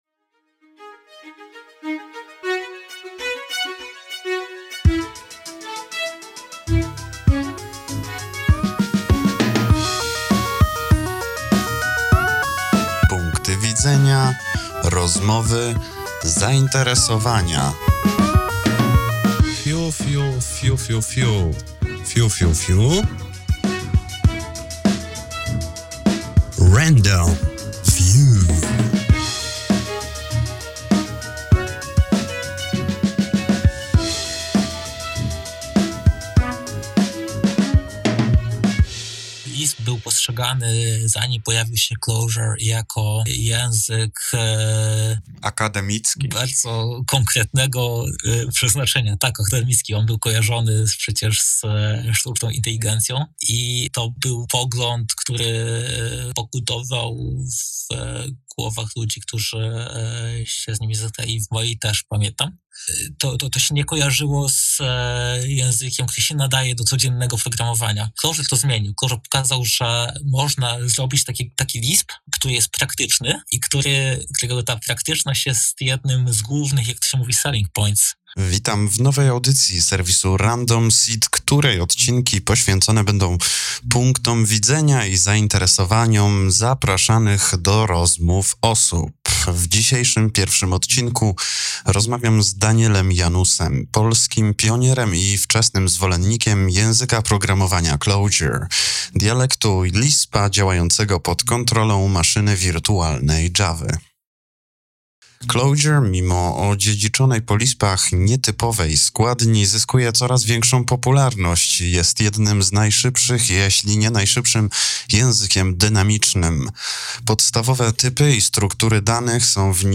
Wywiad